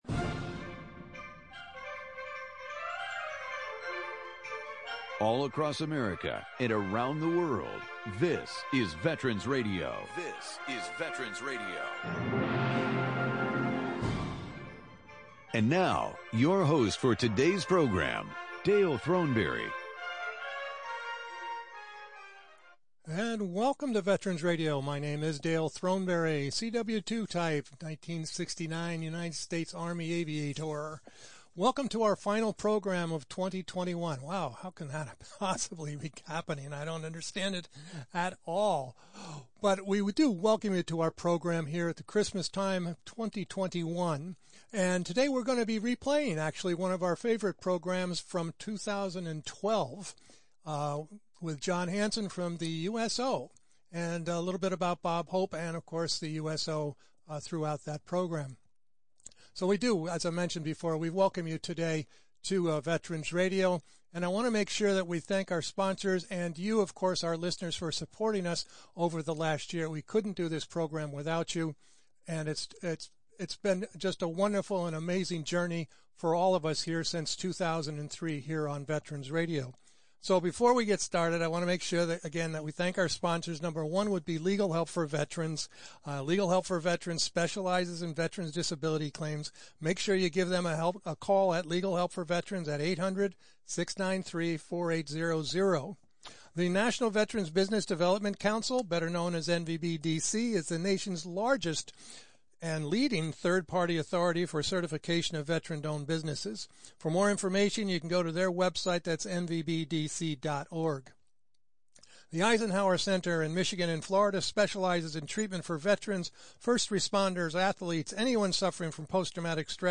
Part of this week’s program is a pre-recorded program about the history of the USO.